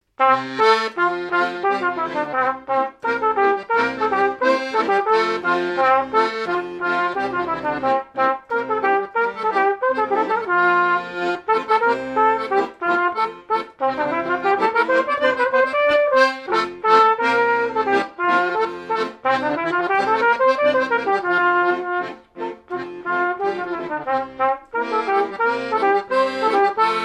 Mémoires et Patrimoines vivants - RaddO est une base de données d'archives iconographiques et sonores.
danse : aéroplane
airs de danses issus de groupes folkloriques locaux
Pièce musicale inédite